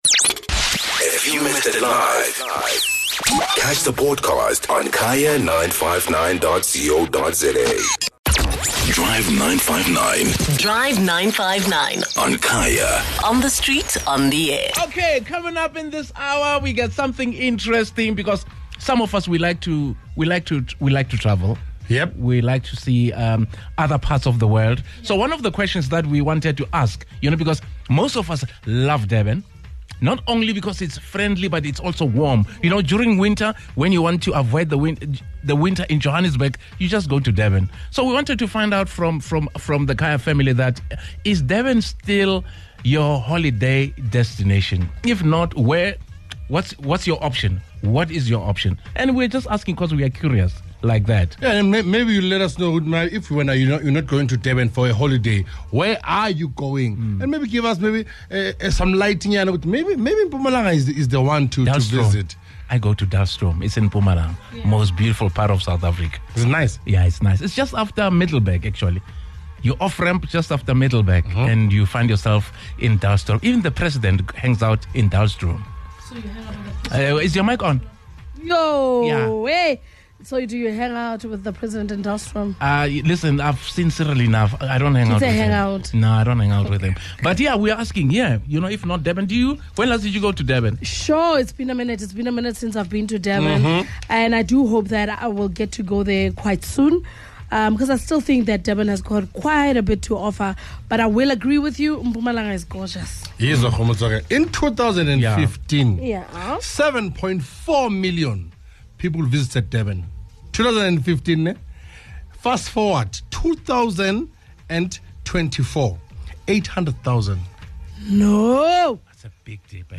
However, in light of the struggles the city has faced in recent years, with poor municipal management, and the aftermath of multiple devastating floods, the city's tourism appeal is declining. Listeners share their views on memories they have from their travels to Durban, and the concerns that they now have.